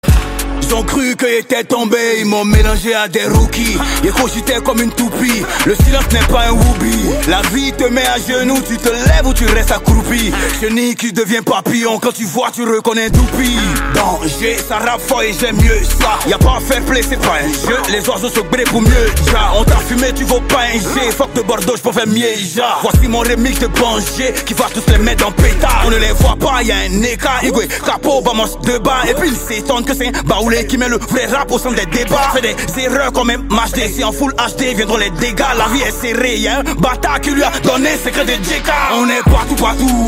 Catégorie Rap